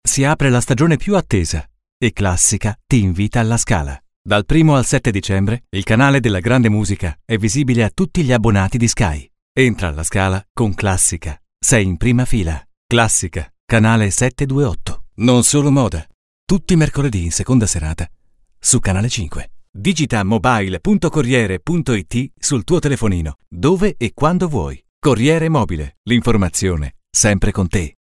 Italiana voice talent.
Kein Dialekt
Sprechprobe: Sonstiges (Muttersprache):